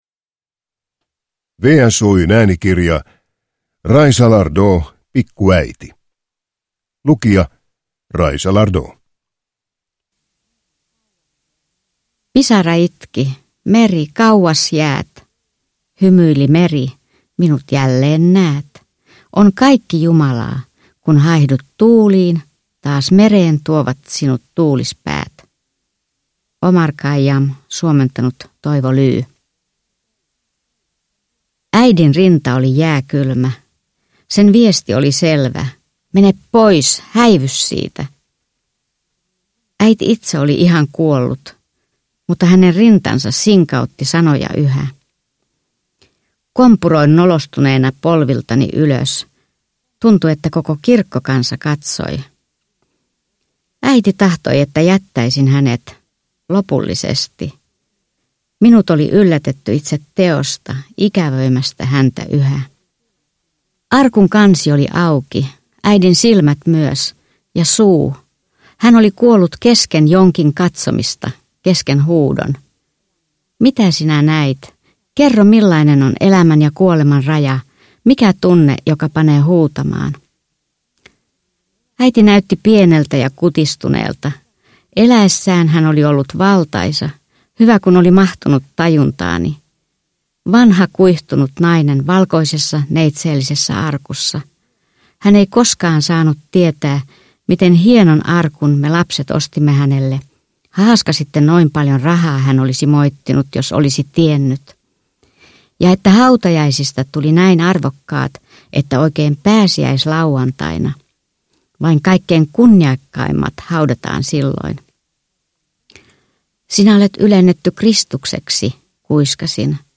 Pikku äiti – Ljudbok – Laddas ner